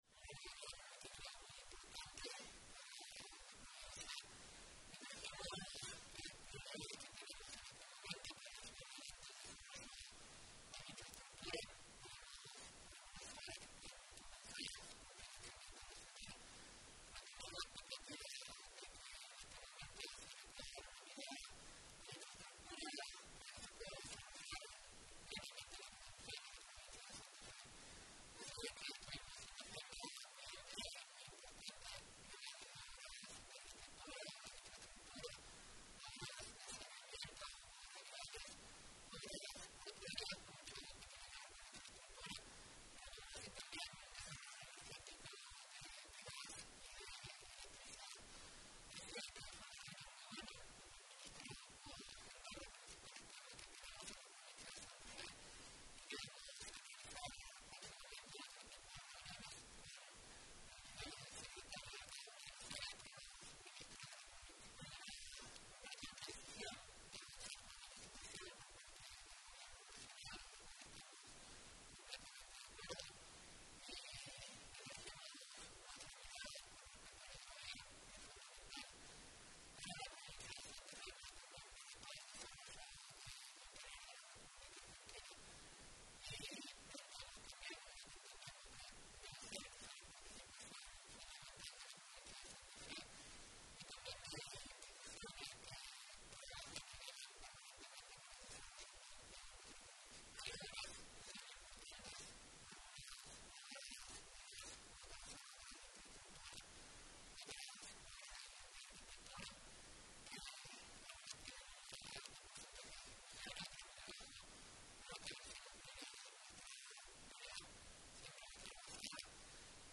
Declaraciones Pullaro